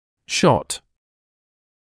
[ʃɔt][шот]снимок; укол; попытка